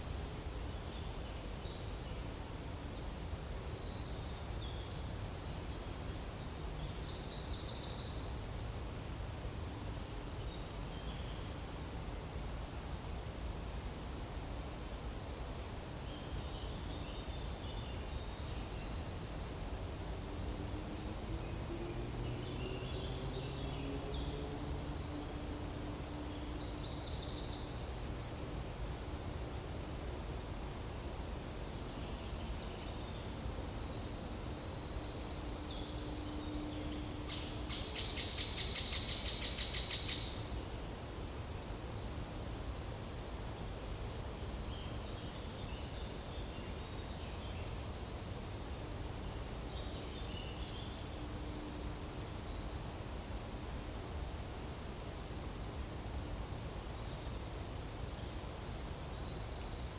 Certhia brachydactyla
Certhia familiaris
Turdus iliacus
Leiopicus medius
Troglodytes troglodytes